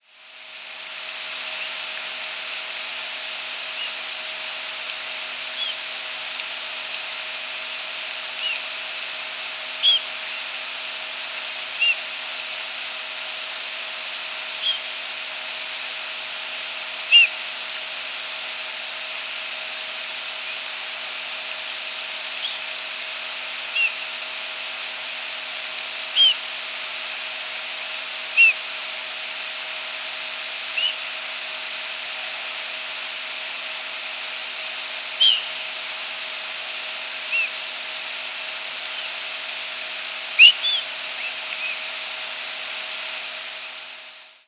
presumed Rose-breasted Grosbeak nocturnal flight calls
Nocturnal flight call sequences: